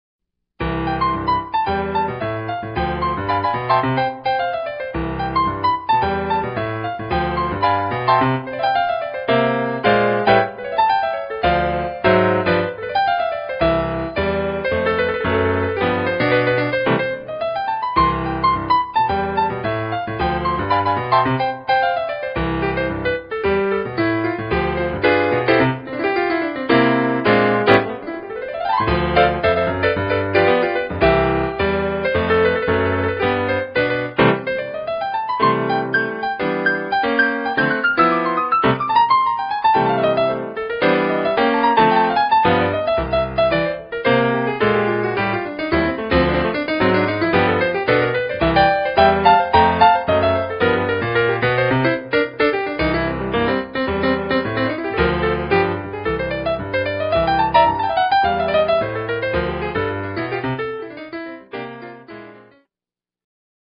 Solo Piano / Keyboard:
Latin Jazz